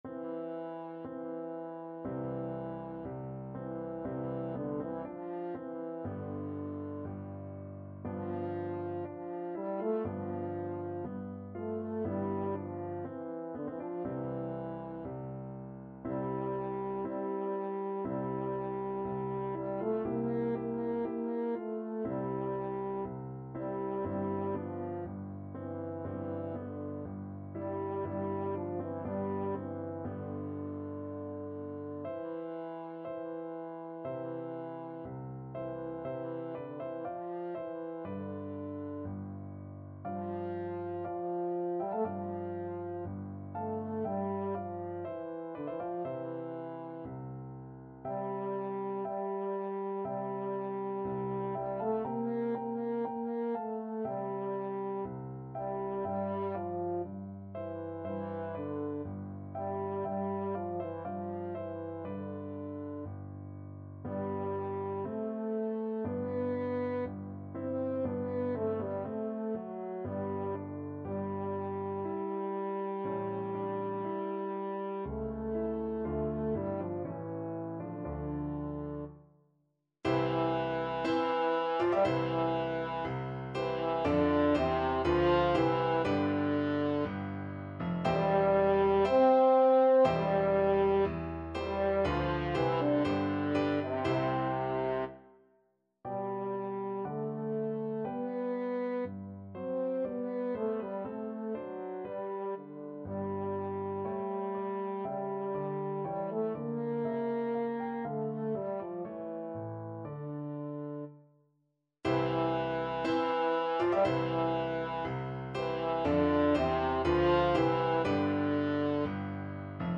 French Horn
4/4 (View more 4/4 Music)
Slow =c.60
Classical (View more Classical French Horn Music)